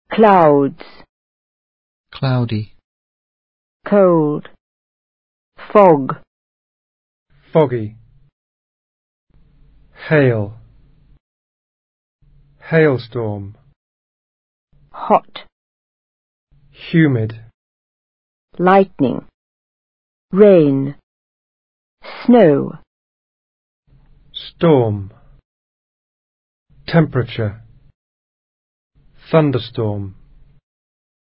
Pronunciation: weather
Estos archivos de sonido contienen la pronunciación individual de algunas palabras en inglés que sirven para denominar el estado del tiempo o fenómenos climáticos: clouds, cloudy, cold, fog, foggy, hail, hailstorm, hot, humid, lightning, rain, snow, storm, temperature, thunderstorm.